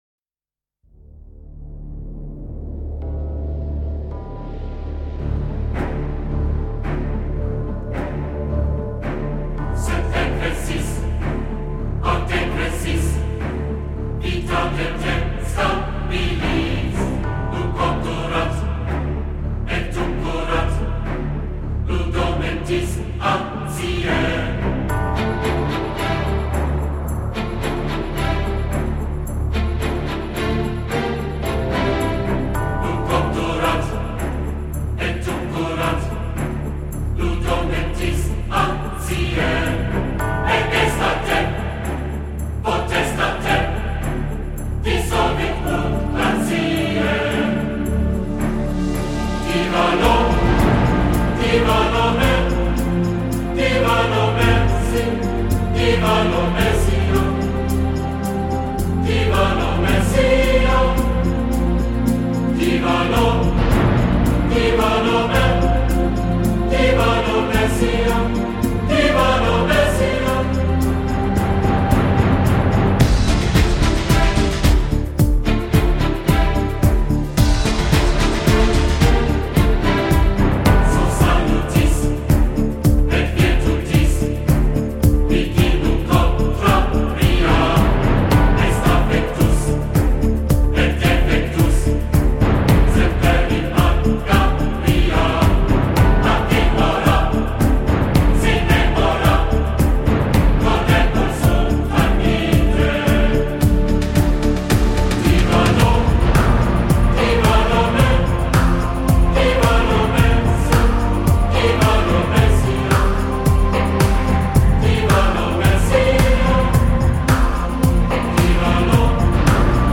中国发烧界最华丽的磁场人声 高级音响专用 环绕声测试碟